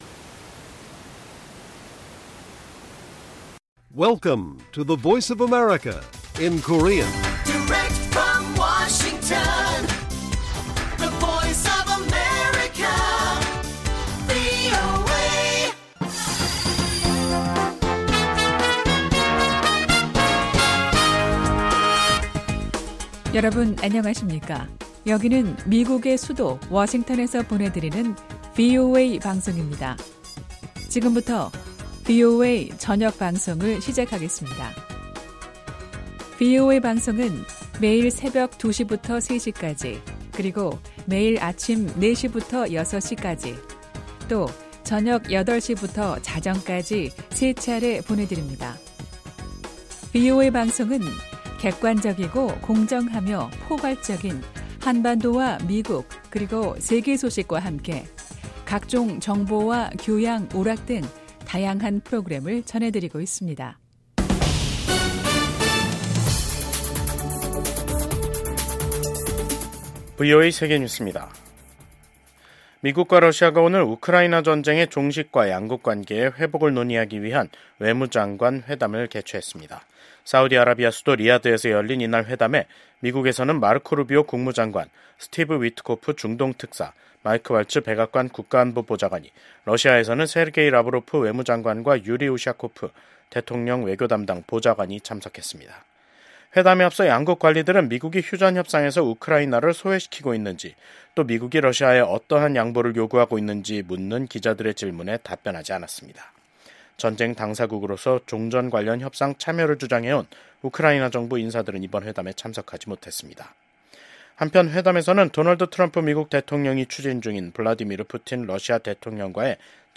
VOA 한국어 간판 뉴스 프로그램 '뉴스 투데이', 2025년 2월 18일 1부 방송입니다. 미국 정부가 북한의 핵·미사일 위협을 비판하며 한국, 일본 등 동맹과 긴밀히 협력하고 있다고 밝혔습니다. 한국이 유엔 안보리 회의에서 러시아에 병력을 파병한 북한을 강하게 규탄했습니다. 북한은 미한일 외교장관들이 북한의 완전한 비핵화를 명시한 공동성명을 발표한 데 대해 반발하는 담화를 냈습니다.